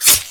spawners_mobs_slash_attack.ogg